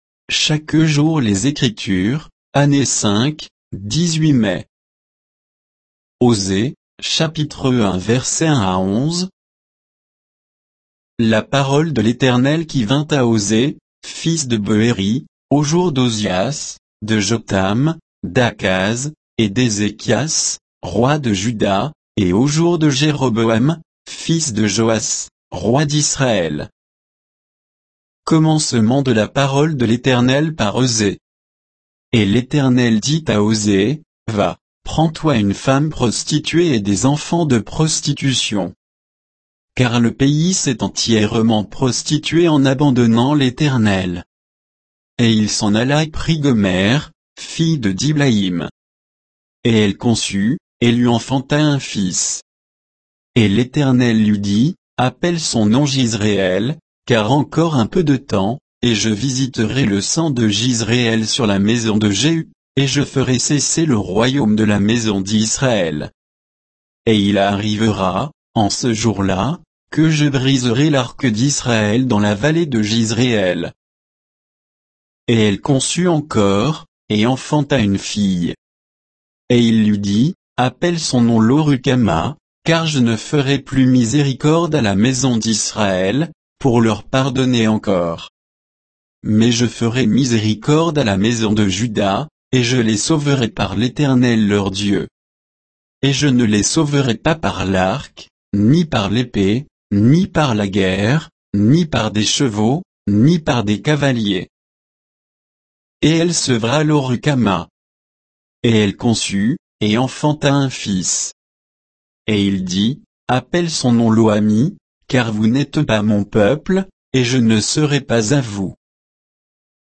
Méditation quoditienne de Chaque jour les Écritures sur Osée 1, 1 à 11